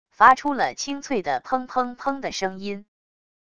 发出了清脆的砰砰砰的声音wav音频